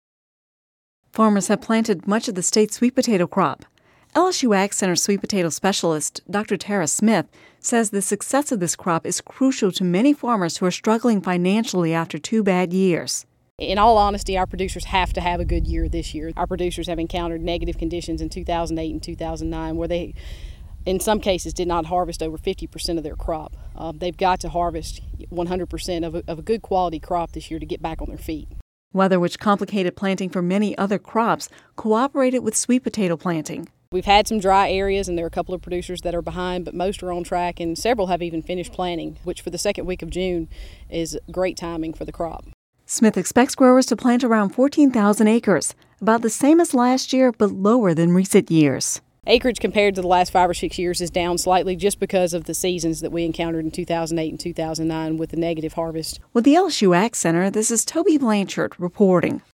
(Radio News 6/21/10) Farmers have planted much of the state’s sweet potato crop.